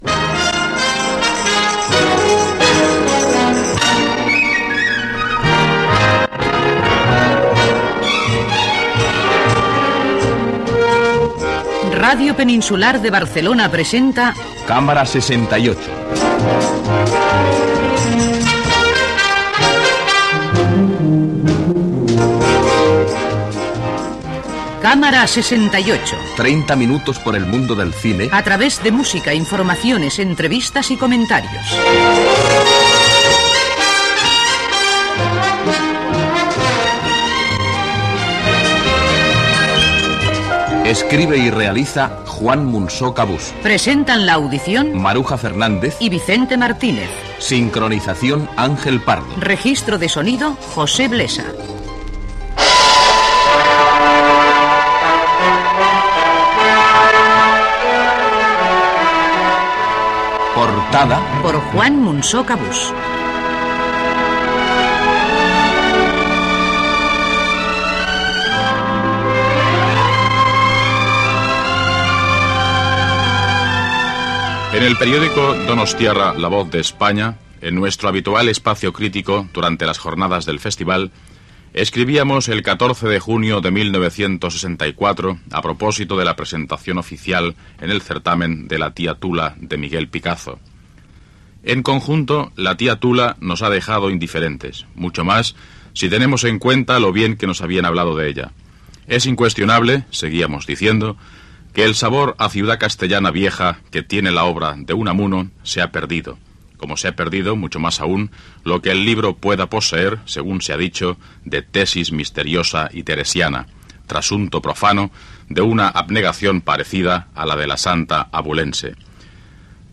Careta del programa dedicat al cinema, portada sobre "La tía Tula".